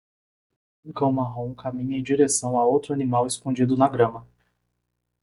Pronounced as (IPA)
/is.kõˈd͡ʒi.du/